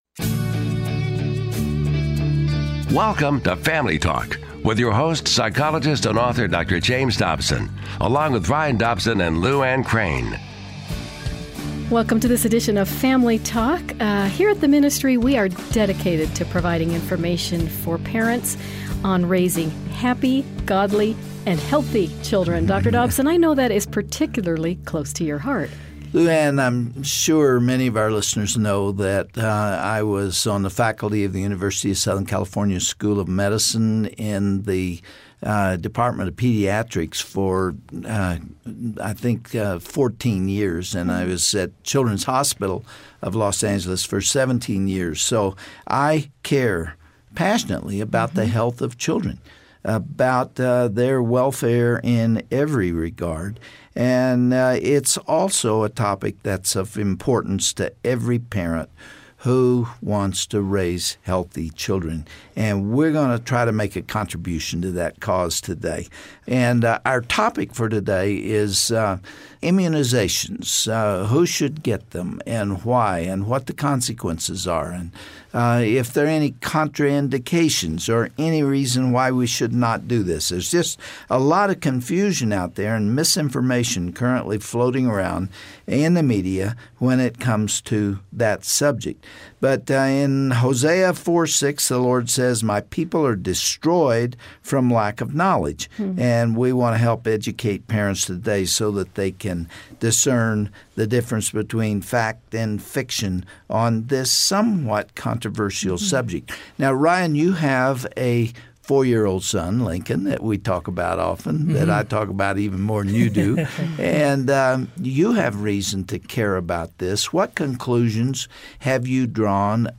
Listen to two medical professionals who will help us get to the bottom of this issue.